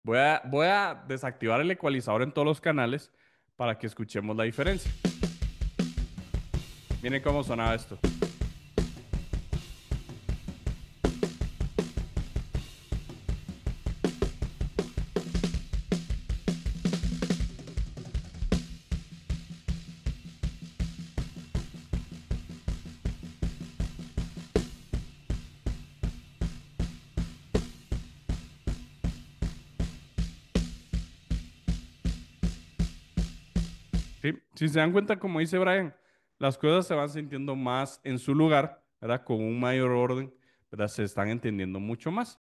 🎚 Activamos y desactivamos el ecualizador para que escuches la diferencia. Con el EQ activo, todo suena con más orden, definición y claridad.